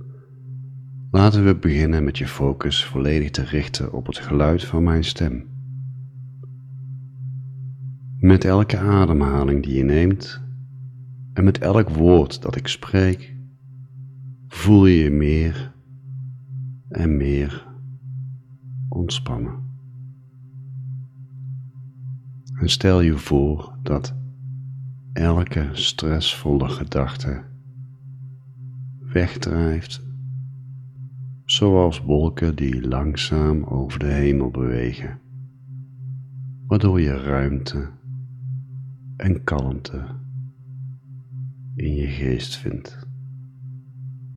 Deze mp3, ingesproken door een ervaren hypnotherapeut, gidst je door diepe ontspanning naar een plaats van persoonlijke kracht. Ontdek technieken om stress onmiddellijk te verminderen en verhoog je…